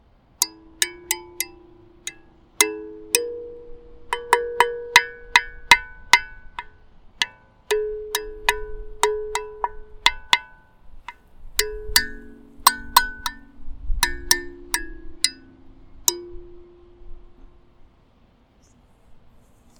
우주_칼림바1.mp3